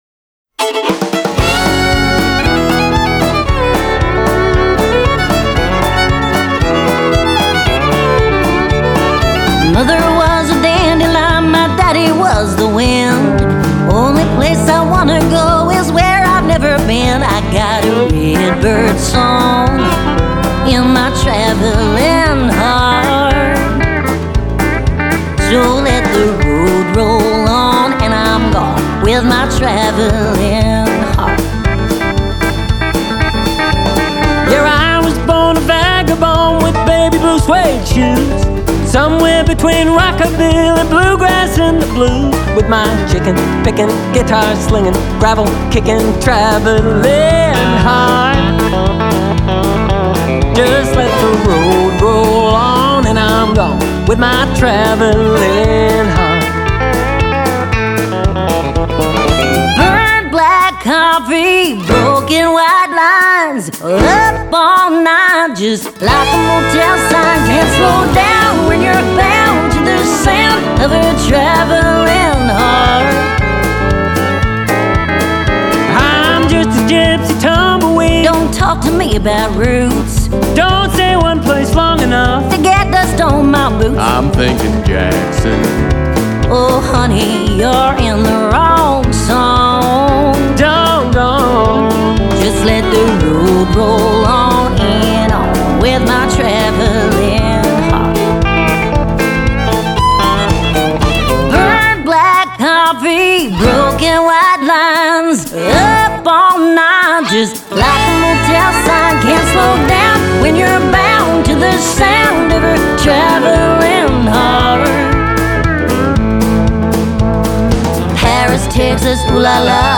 a fun, rollicking country tune